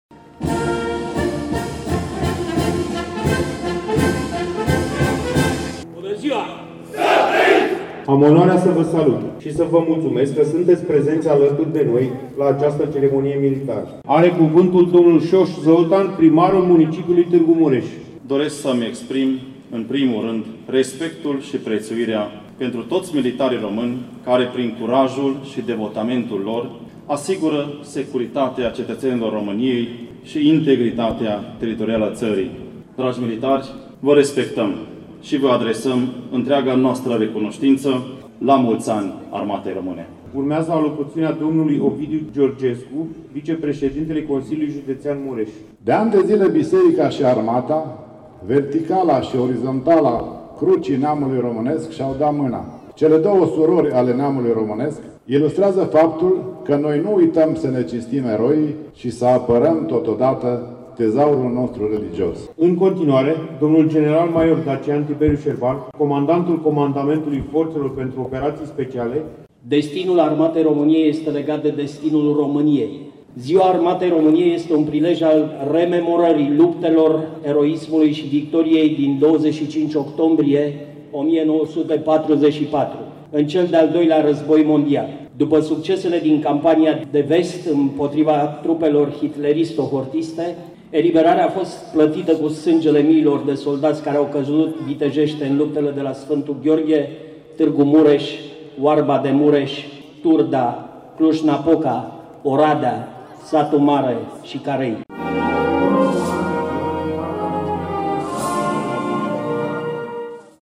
Una dintre activitățile organizate astăzi de Garnizoana Târgu Mureș cu ocazia acestei zile, a fost ceremonia militară și religioasă din Piața Victoriei.